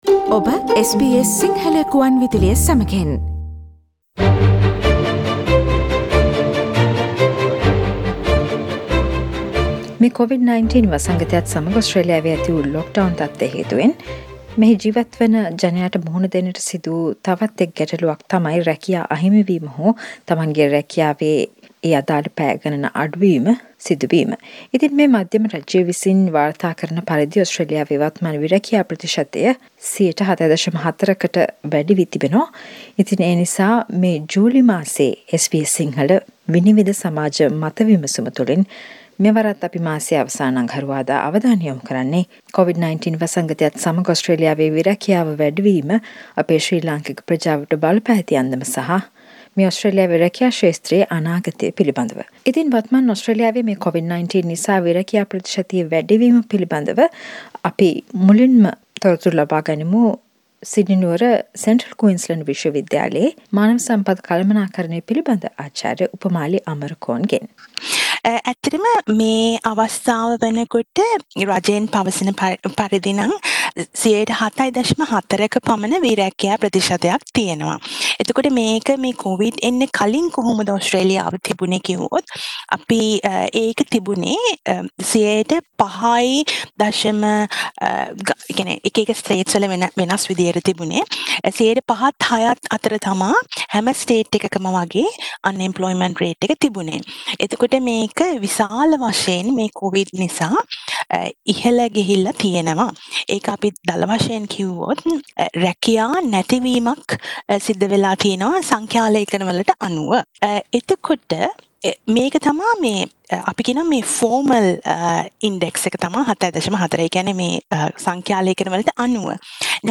SBS Sinhala "Vinivida" monthly discussion focused on current high unemployment rate in Australia and the future of employment post pandemic.